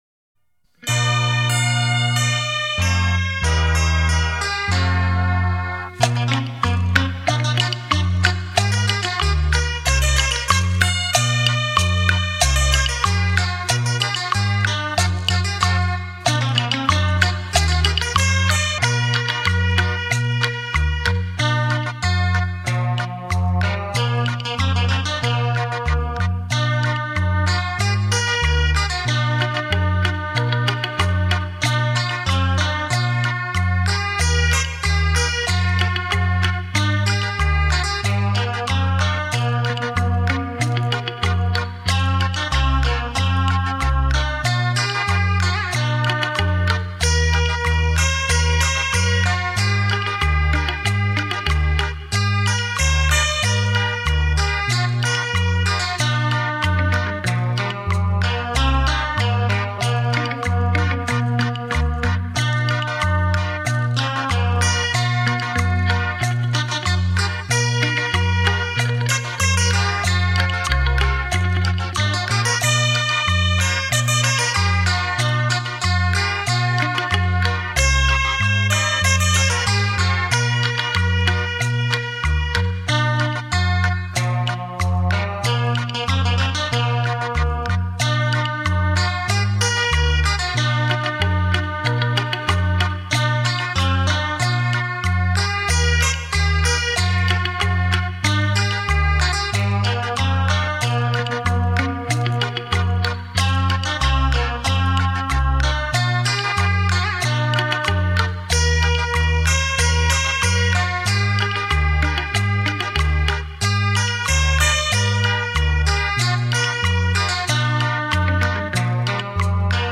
超级立体音场环绕
沉浸在这感性的旋律里